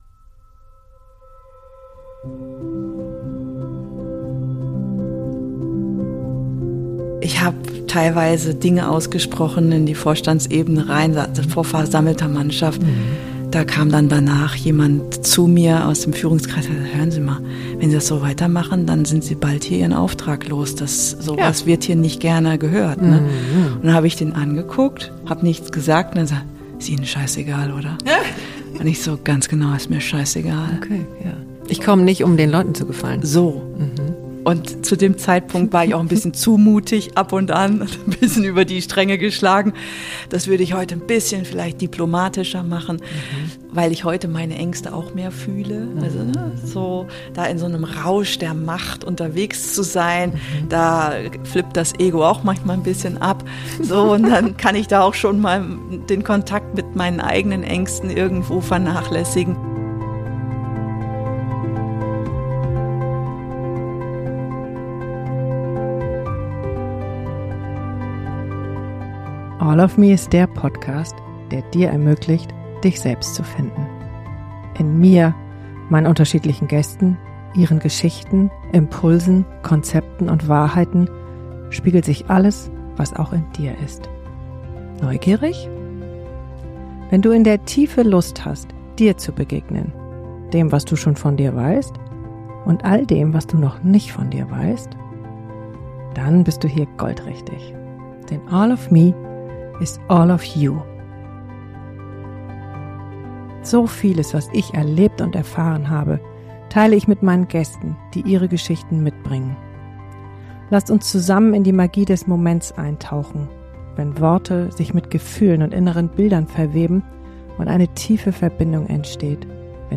Und nach 250 Podcastfolgen kann ich sagen: Sie hat eine der schönsten, weichsten und herzerwärmendsten Stimmen, die bei mir am Mikro saßen, mit einem Thema, das nicht tiefer gehen könnte.